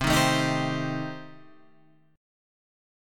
C Minor 6th